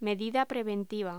Locución: Medida preventiva
voz